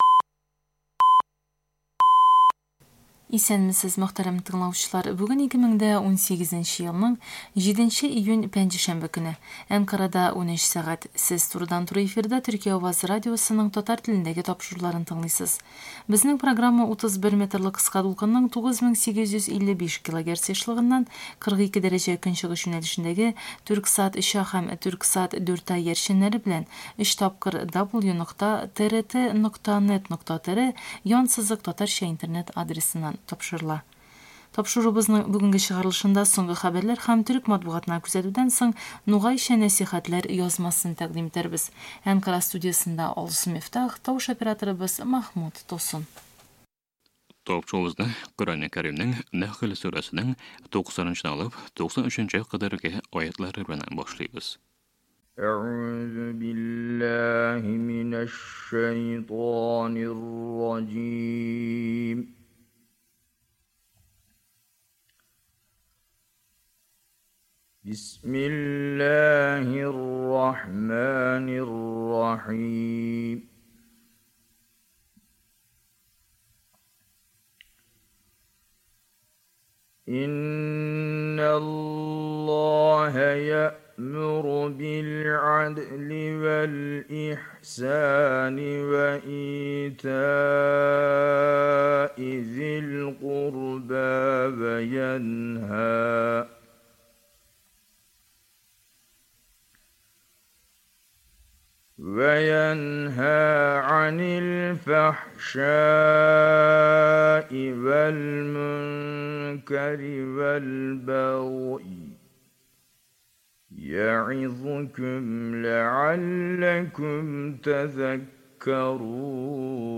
"Төркия авазы" радиосының турыдан-туры эфир язмасы. Тaпшырудa соңгы хәбәрләр, төрeк мaтбугaтынa күзәтү, "Нугaйчa нәсихәтләр" язмaсы.